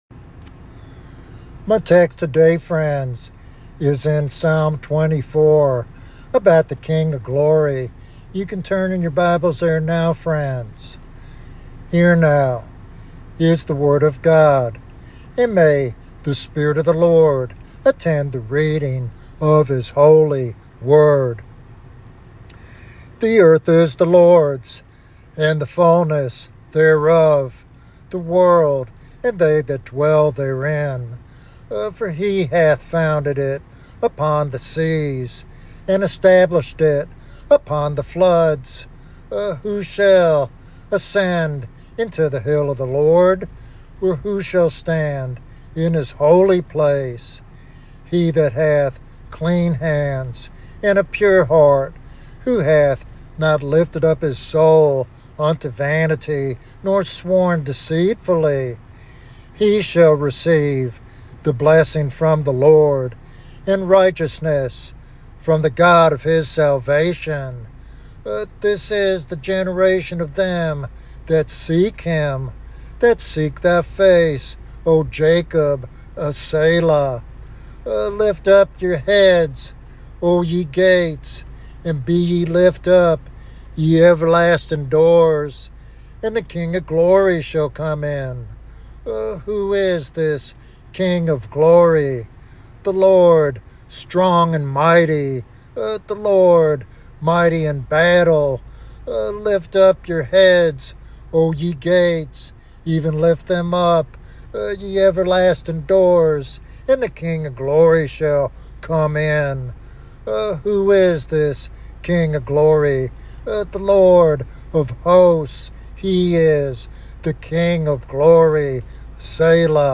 This devotional sermon encourages a deep, sacrificial walk with Christ marked by prayer and commitment.